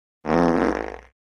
Звуки пердежа, пукания
Человек пукает снова